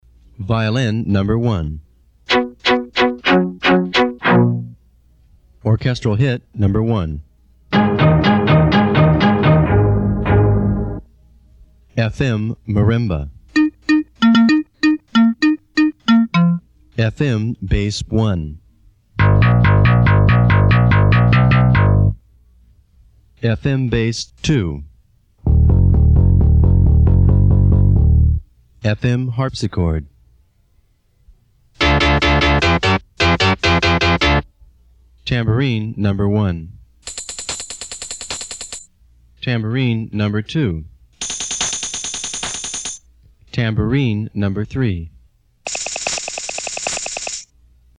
From the Linn EPROM demo tape.mp3